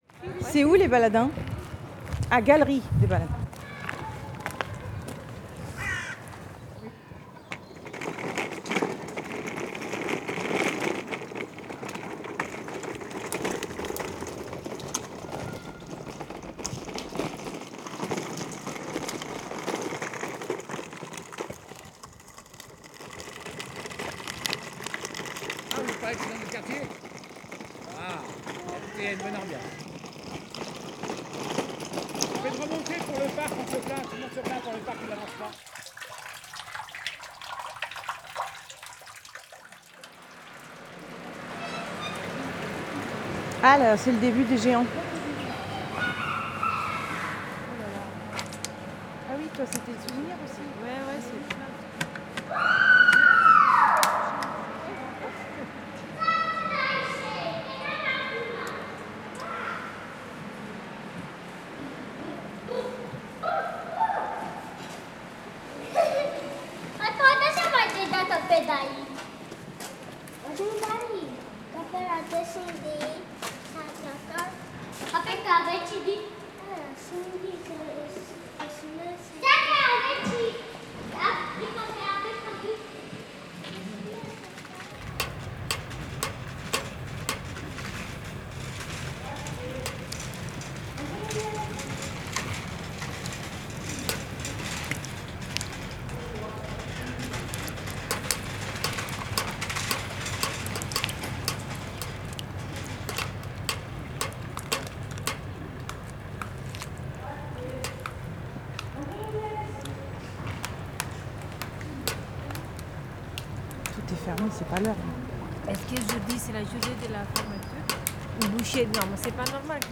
Cette pièce sonore restitue une « dérive » collective à la découverte du quartier des baladins. À travers l’exploration puis l’agrégation d’impressions de « seuils » marquant le passage d’un espace sonore à un autre, d’une ambiance à une autre, cette pièce questionne ce qui, à travers l’écoute, nous guide et nous permet de délimiter des espaces distincts.